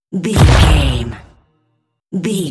Dramatic hit explosion
Sound Effects
heavy
intense
dark
aggressive
the trailer effect